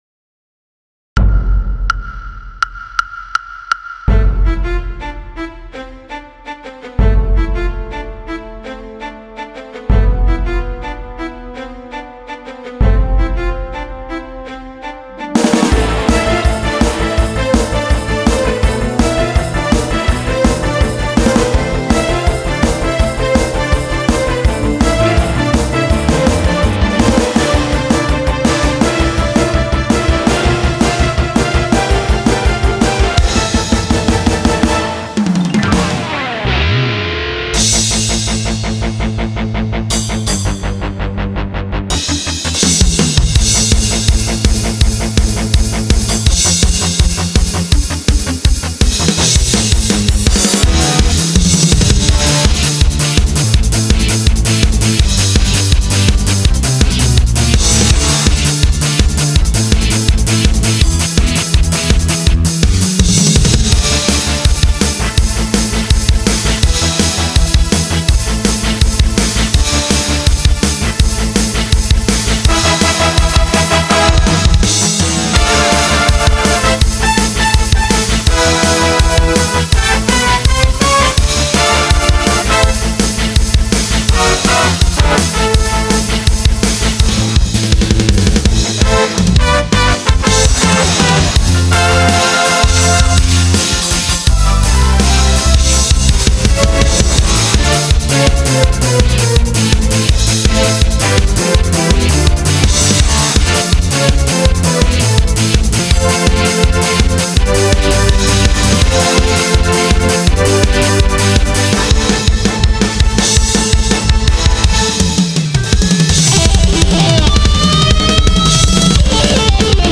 תקשיבו להרמוניה ולגרוב המיוחד במקצבים, יש כאן נפח מלא וסוחף.
לא מסוגל להקשיב לאוטוטיון הזה ! אבל הליווי מצוין.